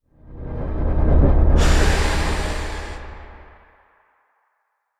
conjuration-magic-sign-circle-outro.ogg